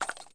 Ride Sound Effect
Download a high-quality ride sound effect.